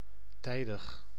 Ääntäminen
IPA: /pɔ̃k.tɥɛl/